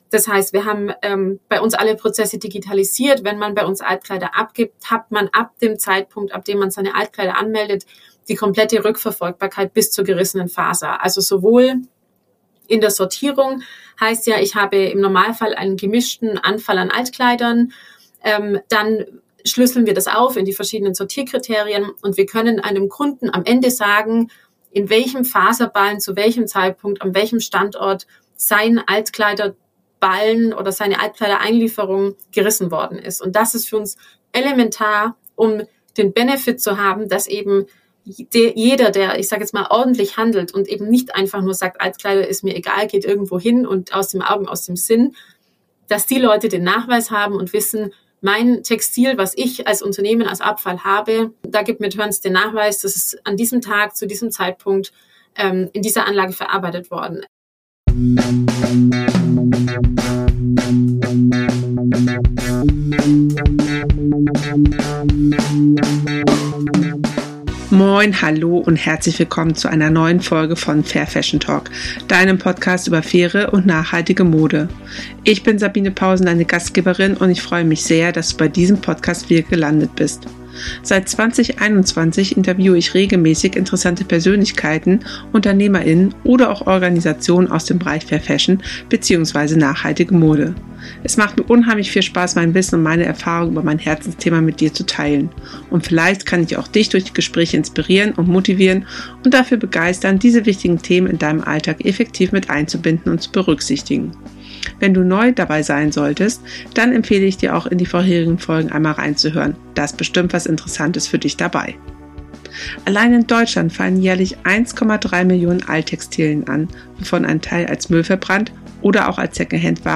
1 #77 - So wird Nachhaltigkeit gelebt! Ein Interview